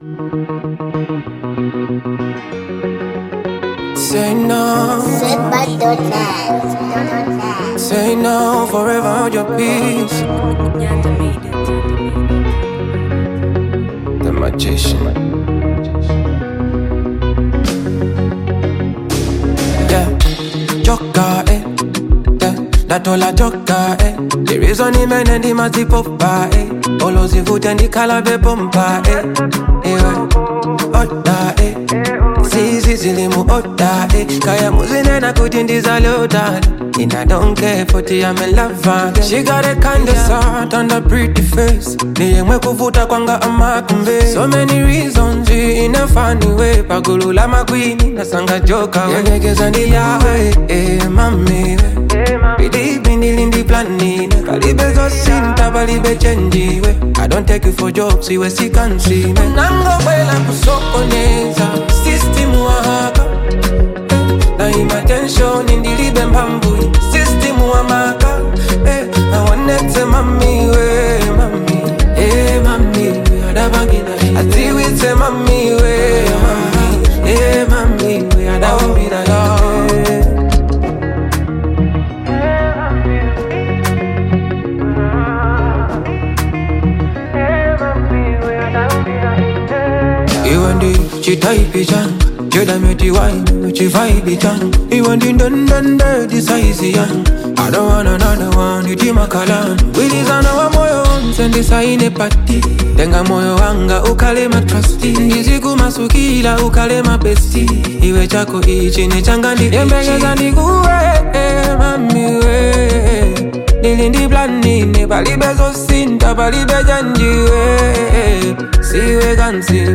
making it a great listen for fans of modern, edgy music.